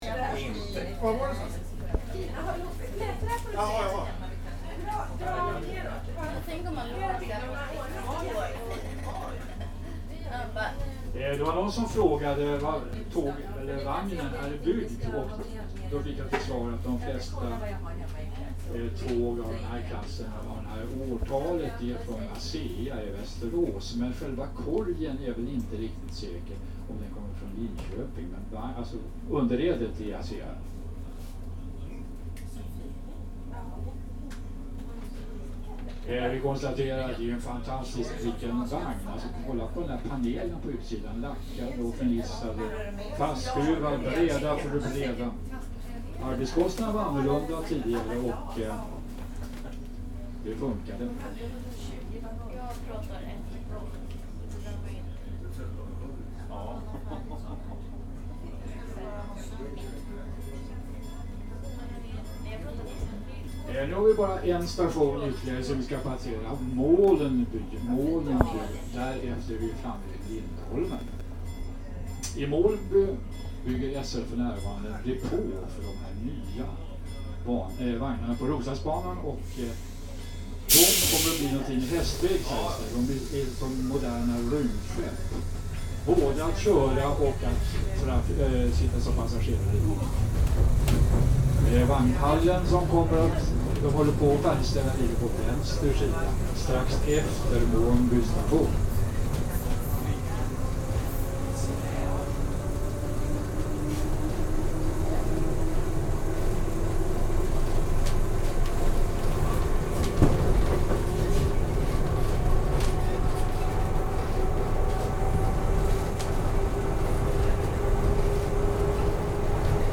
Roslagsbanan, train from 1934 with a guide (5)
This is a train built in 1934 and used up until the 1990s - and in this version of the soundscape there is a crowd going with the train alongside a guide.
• Train
• Railroad